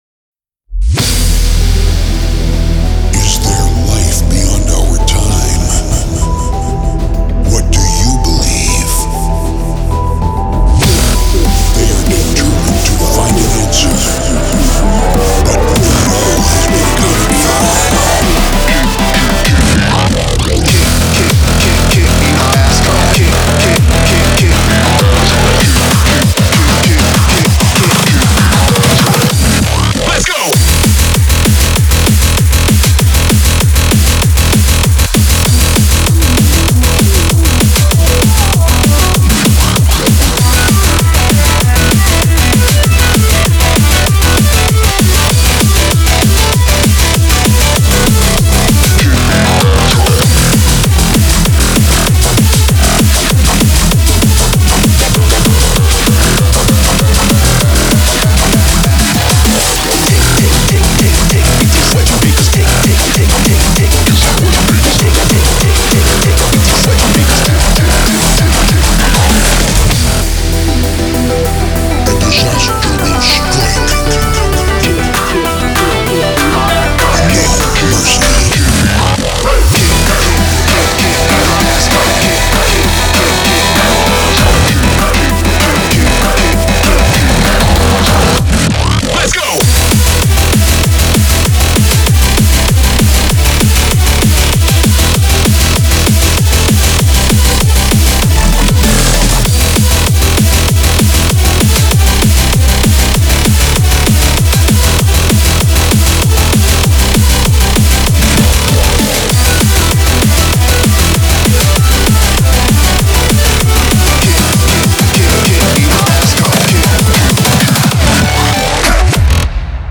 BPM195
Audio QualityPerfect (High Quality)
Comments[FRENCHCORE]